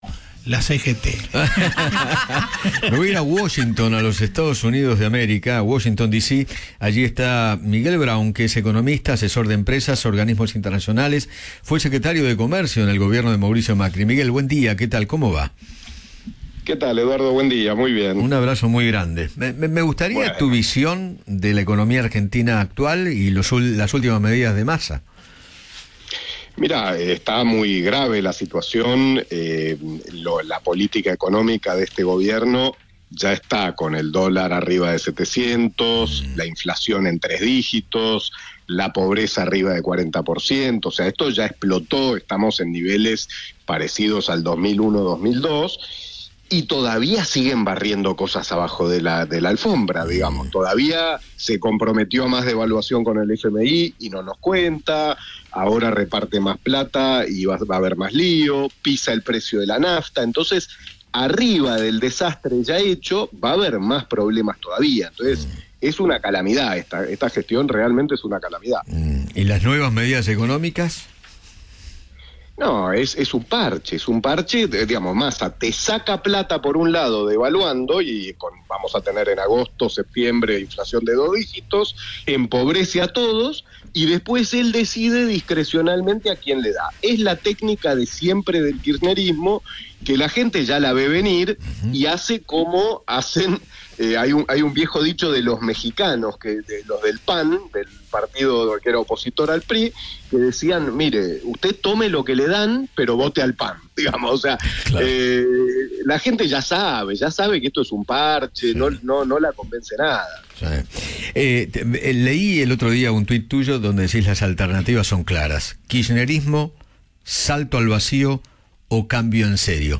El economista Miguel Braun dialogó con Eduardo Feinmann sobre la coyuntura económica del país, tras las nuevas medidas de Sergio Massa para paliar los efectos de la devaluación.